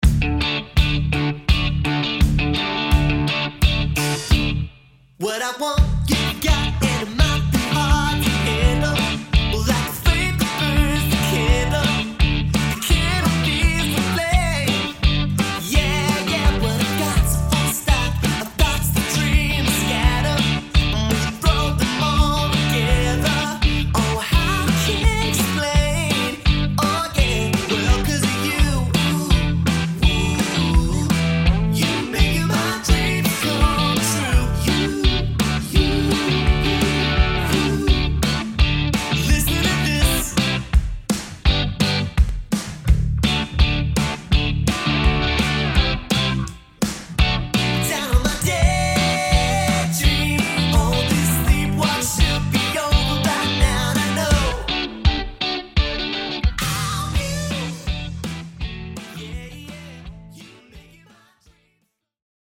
• 4-piece
Vocals / Guitar, Bass, Guitar, Drums